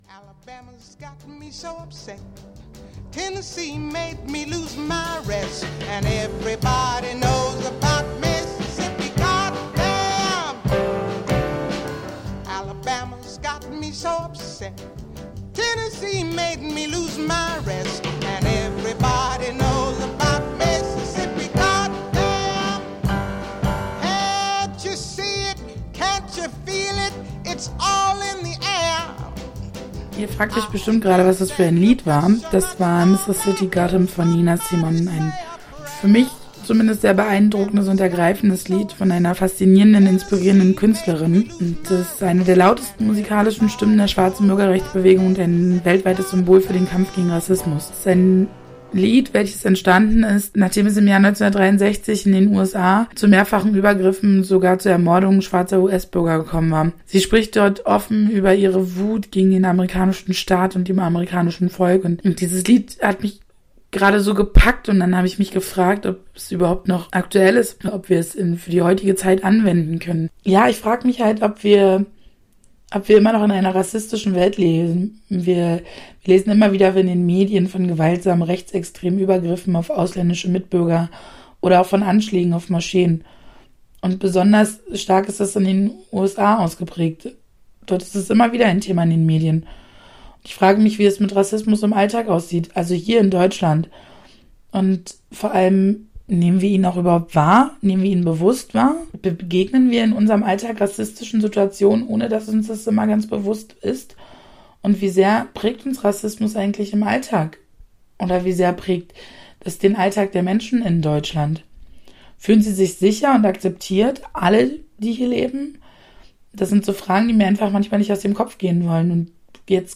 Wird Rassismus überhaupt als solcher bemerkt? In unserem Hörfunkbeitrag thematisieren wir Alltagsrassismus in Deutschland. Dazu haben wir Menschen in Berlin befragt.
Die ganze Sendung lief auf Radio Alex.